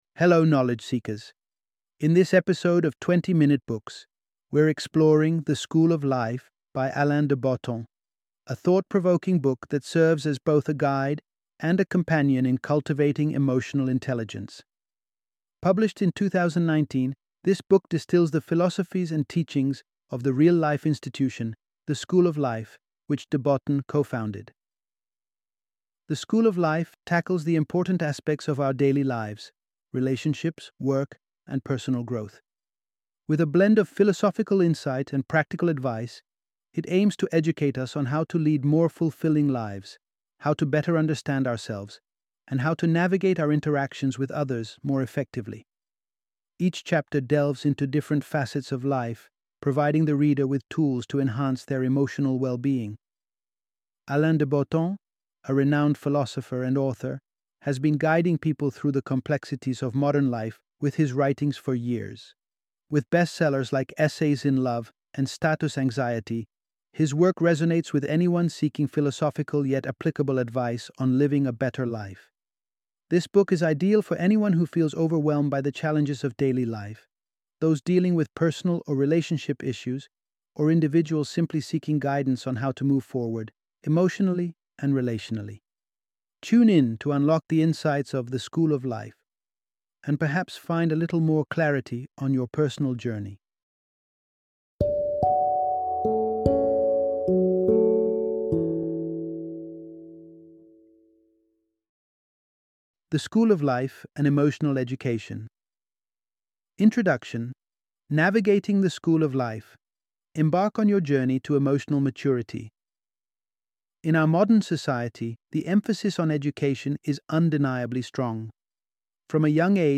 The School of Life - Audiobook Summary